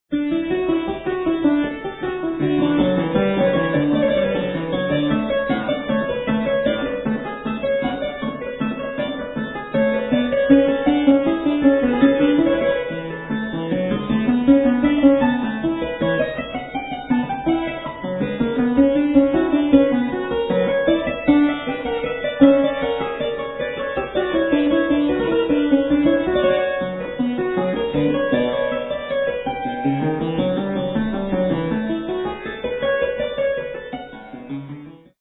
clavichord